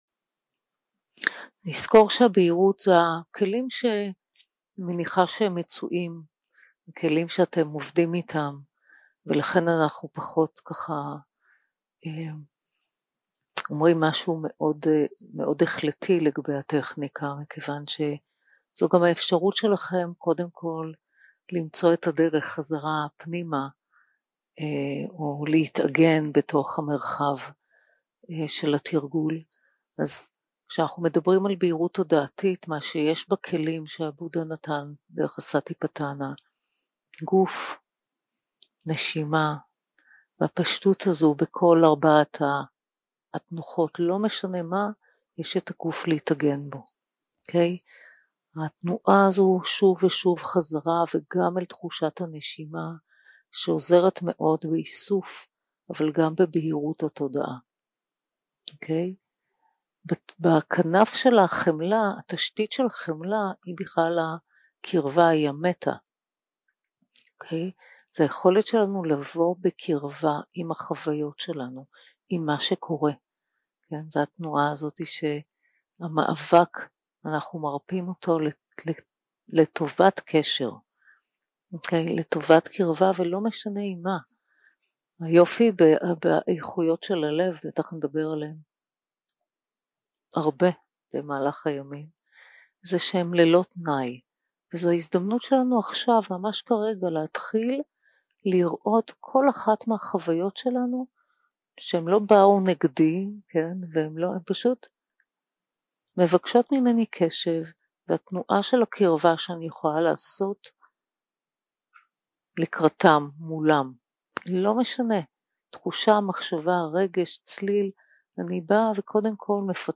יום 2 – הקלטה 3 – בוקר – הנחיות למדיטציה – טיפוח גישה של חמלה 0:00 0:00 סוג ההקלטה: Dharma type: Guided meditation שפת ההקלטה: Dharma talk language: Hebrew